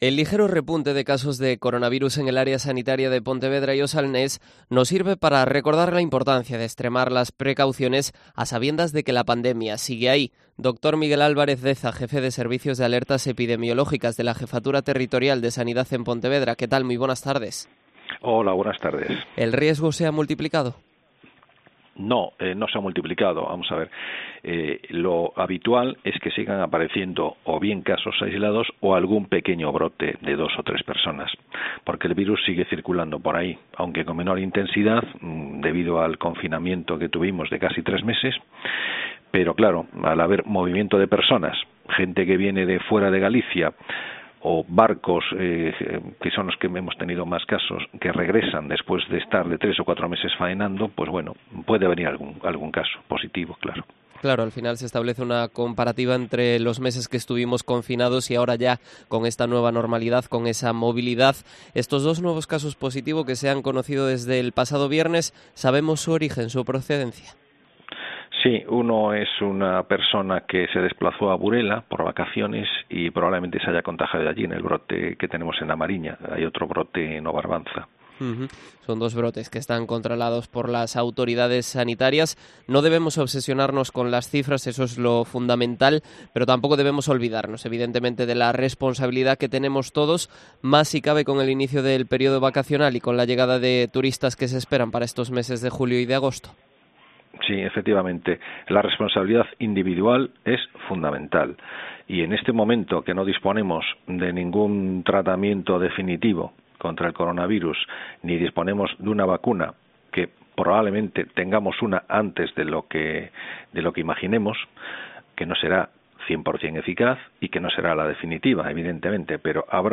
Entrevista con el doctor epidemiólogo